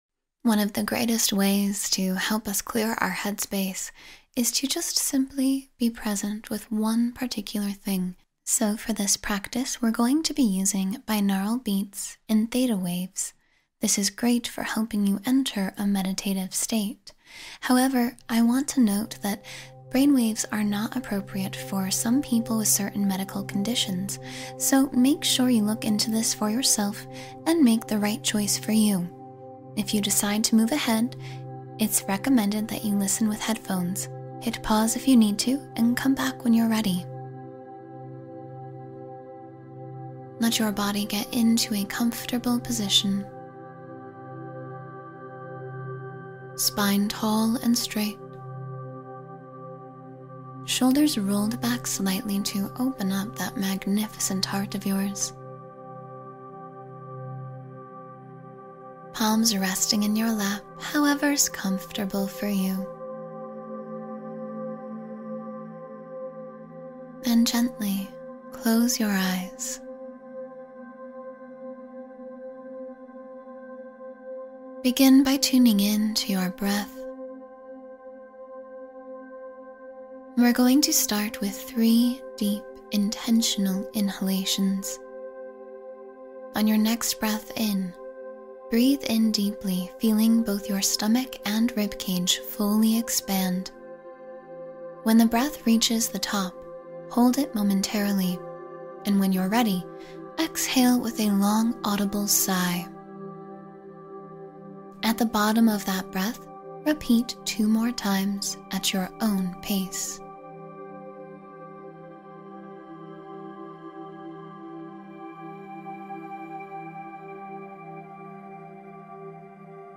Clearing Your Mind with Theta Waves and Binaural Beats